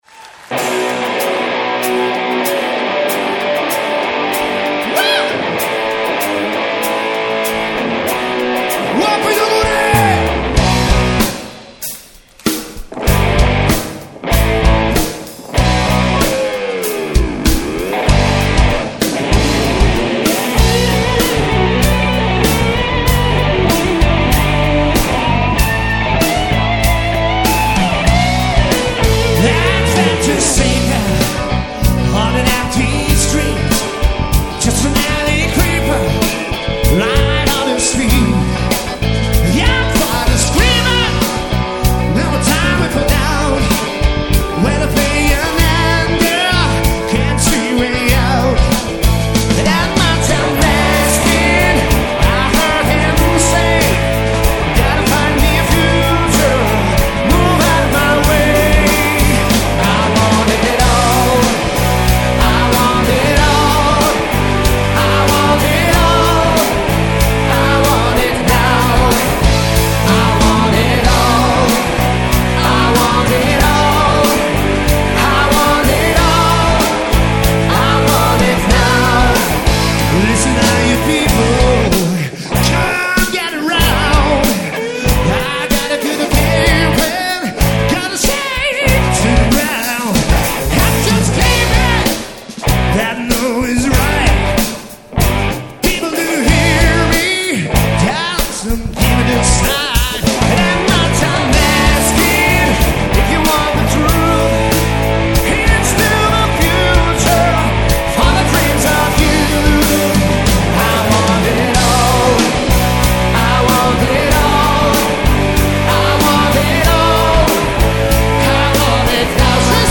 Die Aufnahmen stammen vom ersten Konzert Dezember 2005.